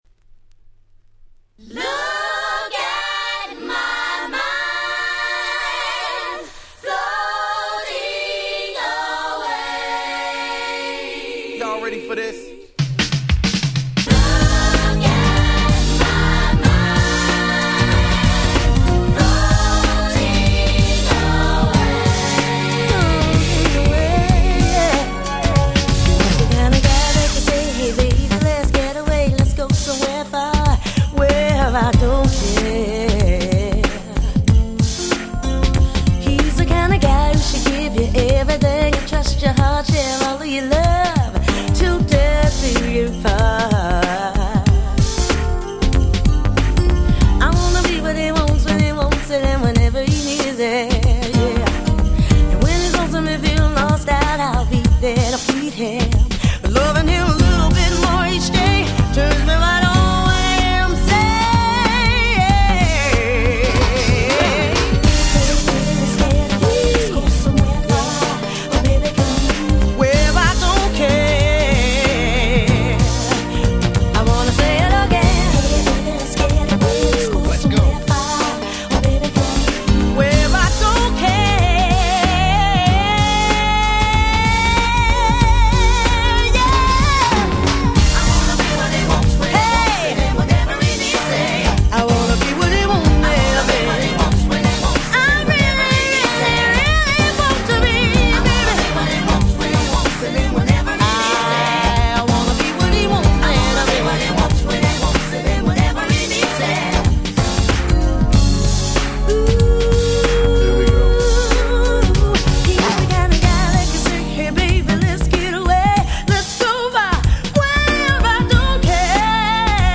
※試聴ファイルは別の盤から録音してございます。
Nice 90's R&B♪